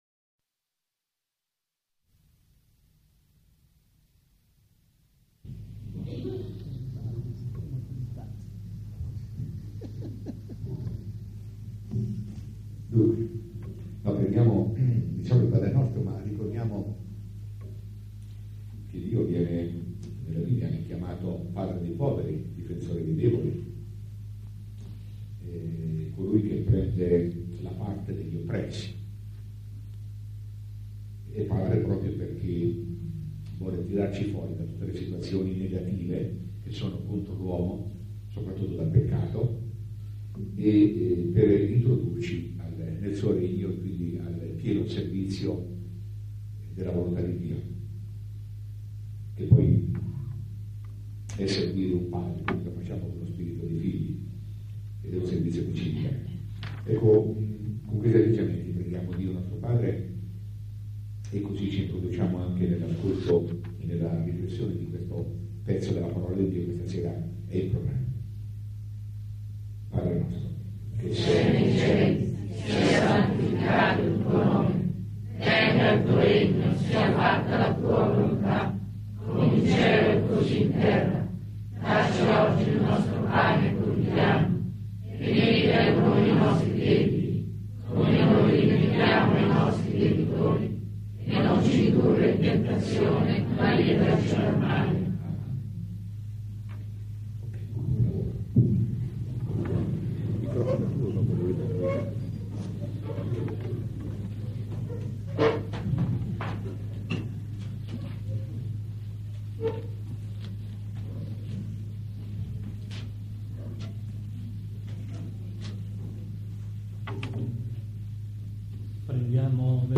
registrazioni delle lezioni